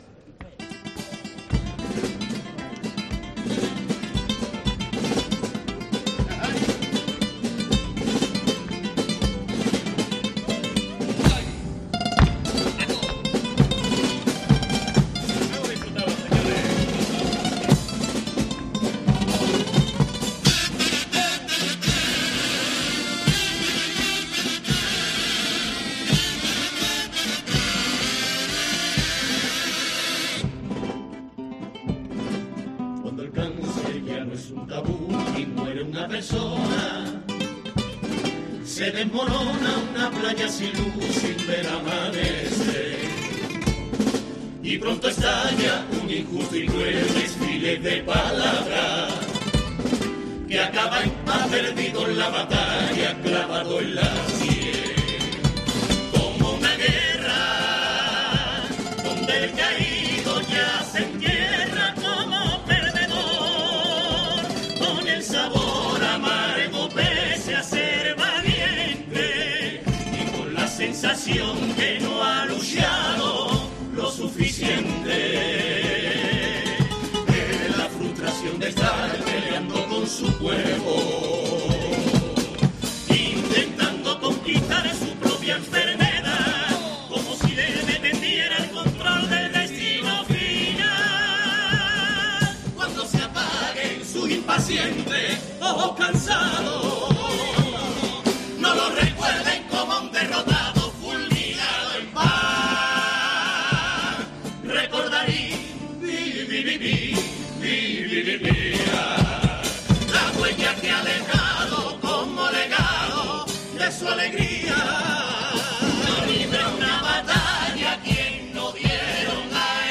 Carnaval de Cádiz
Pasodoble a la lucha contra el cáncer de Los conquistadores